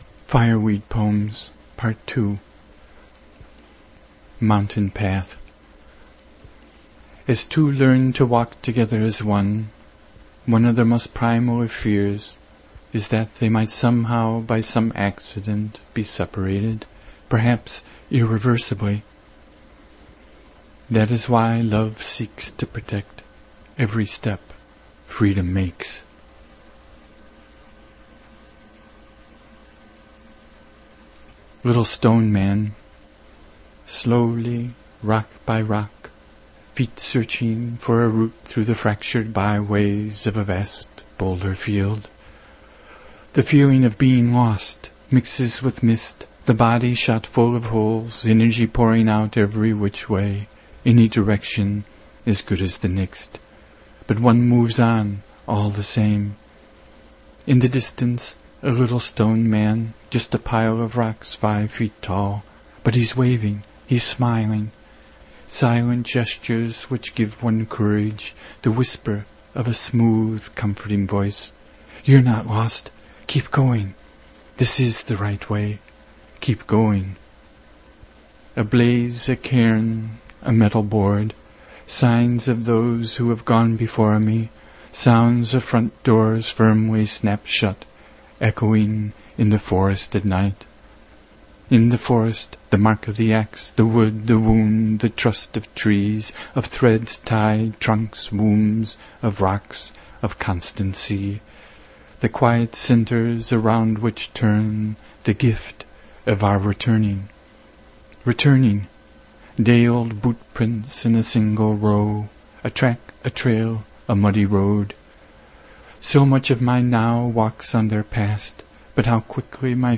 I perform the rhythm—accents, pauses, emphasis, breaths, etc—